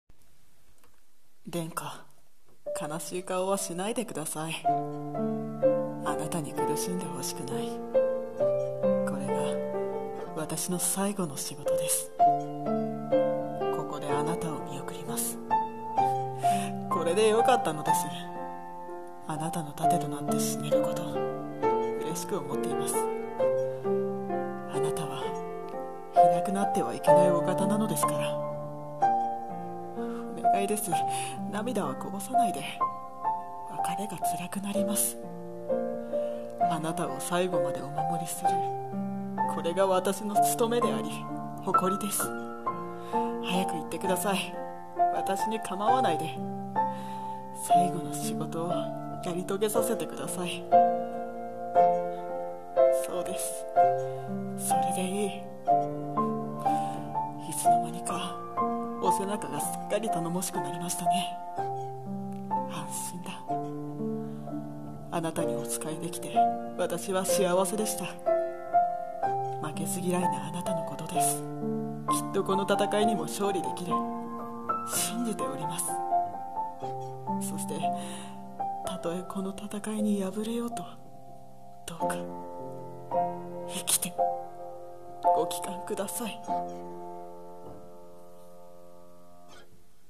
【一人声劇】「従者の最期」